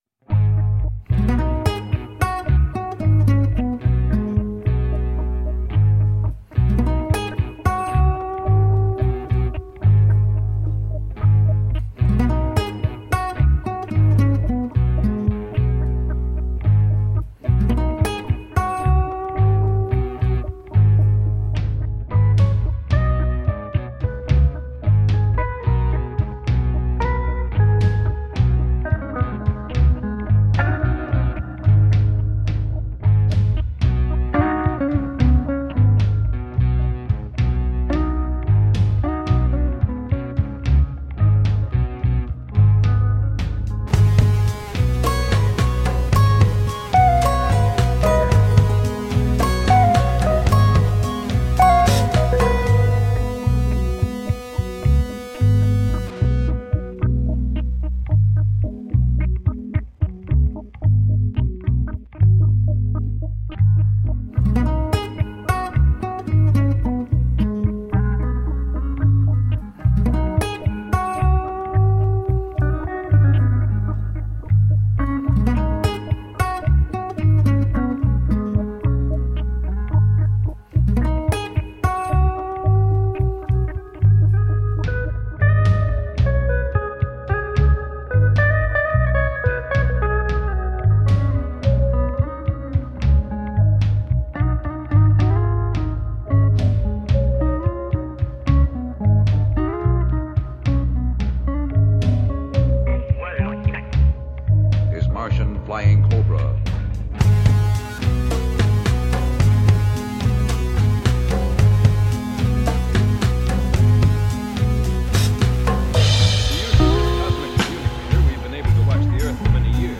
Tagged as: Jazz, Experimental, Funk, Chillout